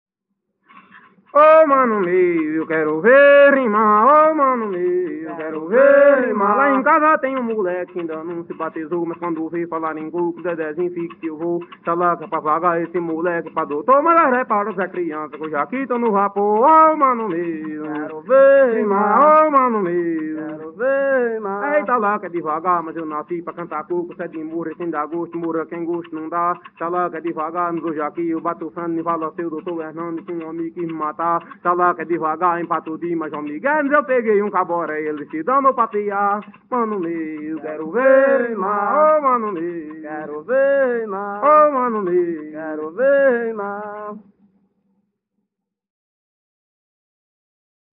Coco embolada/galope - ""Quero ver rimar.""